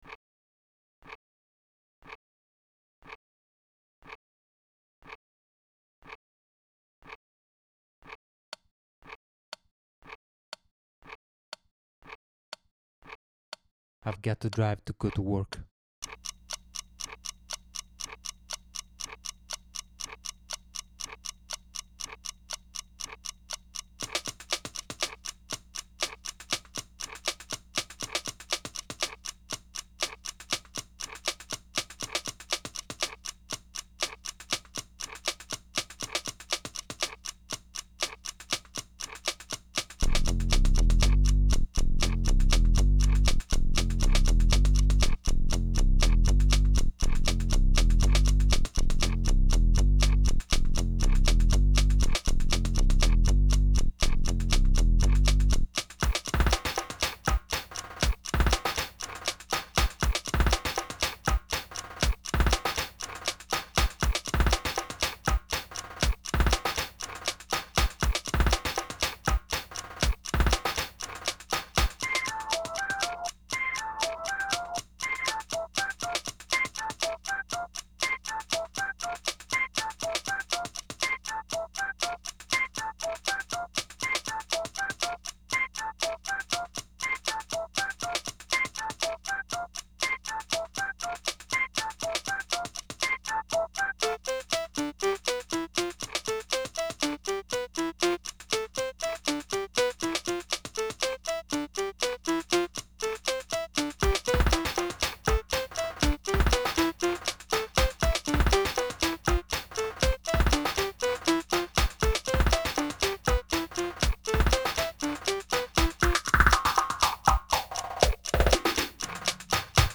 This time we get some manic keyboards and basslines
because the track gets quite ethereal